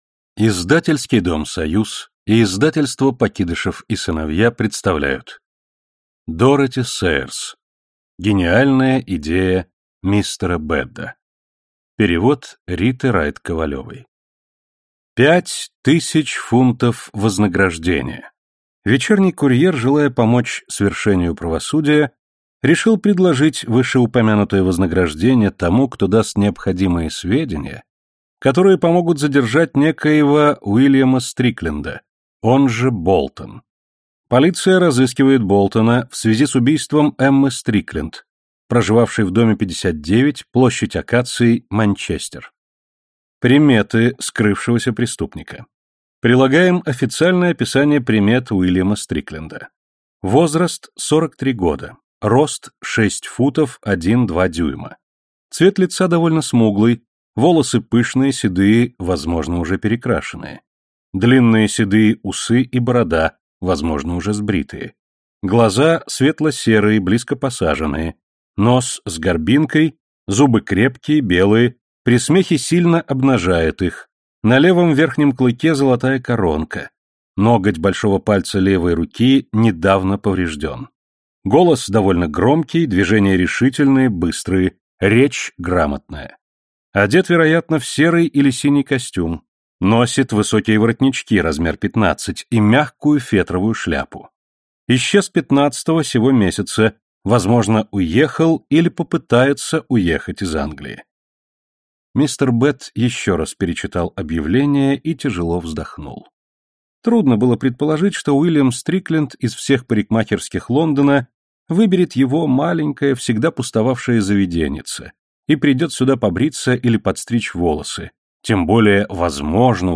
Аудиокнига Гениальная идея мистера Бедда | Библиотека аудиокниг